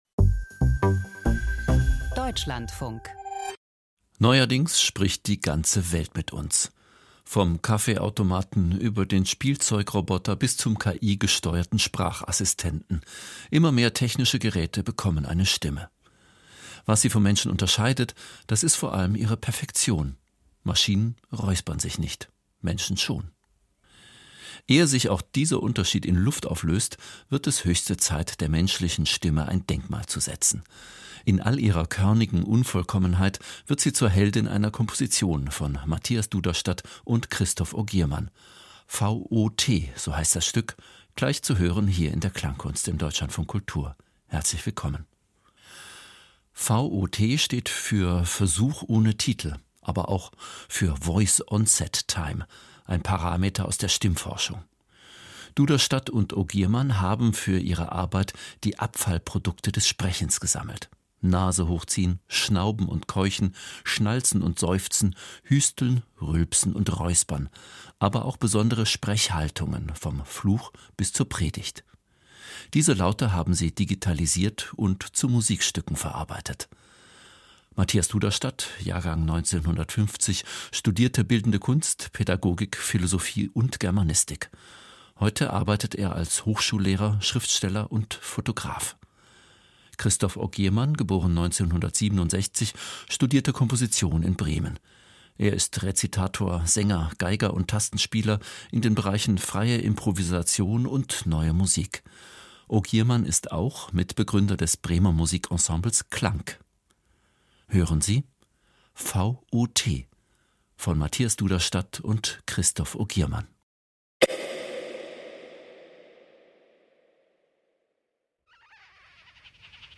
Quantenphysik als Spiel mit Text und Musik. Eine Einladung, verschiedene Perspektiven auf unsere Wirklichkeit einzunehmen.
Email Audio herunterladen • Wort-Klang-Komposition • Keine Angst vor Quantenphysik! Hier läuft kein wissenschaftlicher Vortrag, sondern ein sinnlich erfahrbares Spiel mit Text und Musik, das klangvoll dazu einlädt, über das Verhältnis von Raum und Zeit nachzudenken.